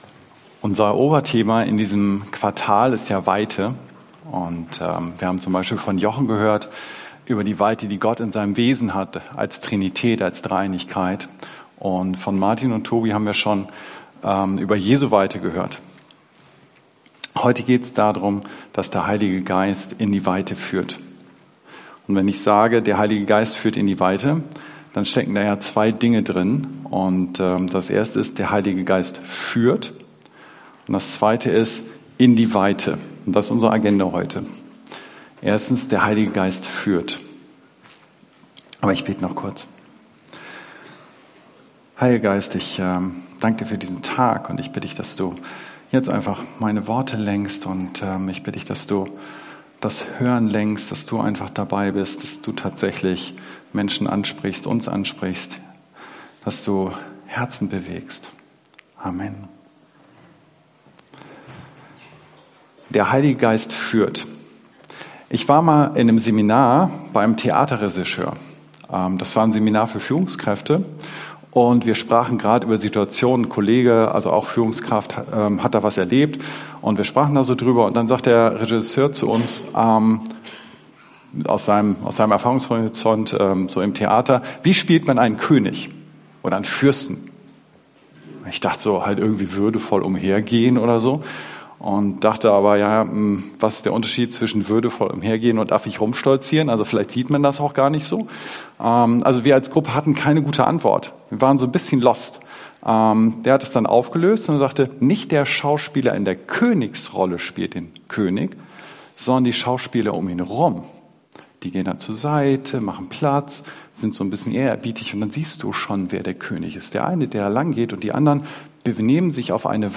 Dienstart: Predigt